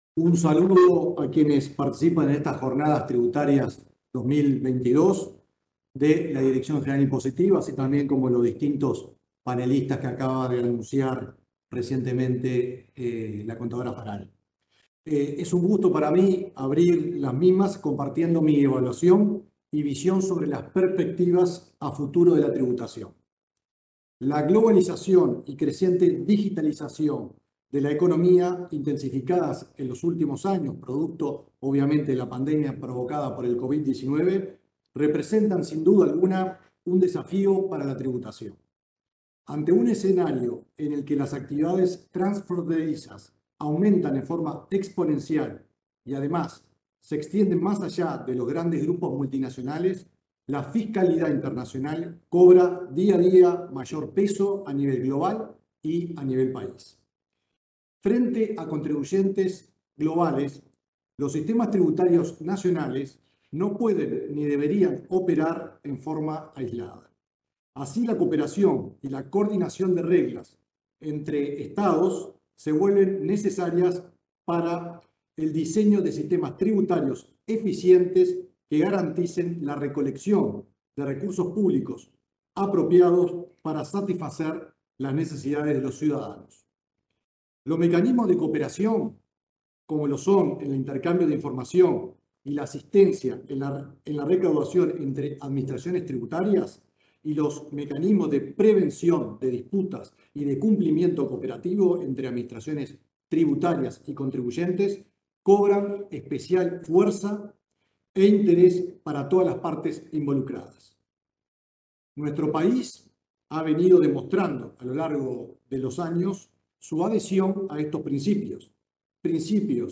Palabras del subsecretario de Economía y Finanzas, Alejandro Irastorza
Las Jornadas Tributarias 2022, evento organizado por la Dirección General Impositiva, se realizan entre el 29 y 30 de noviembre, en modalidad virtual.
El subsecretario de Economía y Finanzas, Alejandro Irastorza, disertó en la apertura.